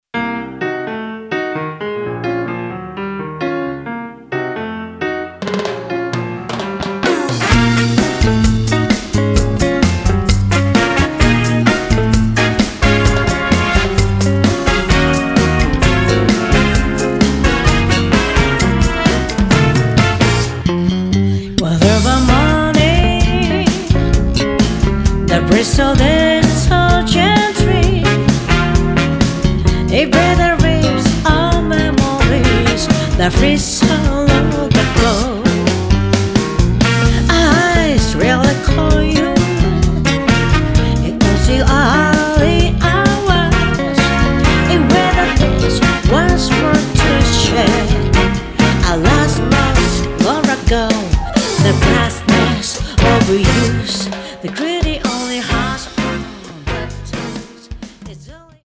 ボーカリスト担当